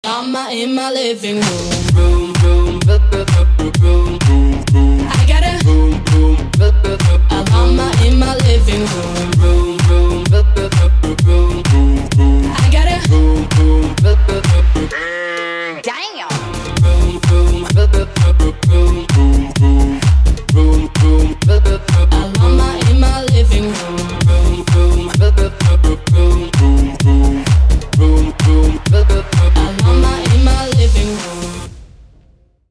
позитивные
веселые
женский голос
dance
Electronic
house
electro house